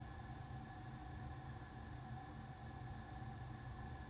今回は防音設備が整った音楽スタジオで、アロマディフューザー３機種と加湿器の音量を実際に計測・録音しました。
加湿器37db34db
※iphone15で各機器から50cm離して録音しました。
一般加湿器-カット4秒.m4a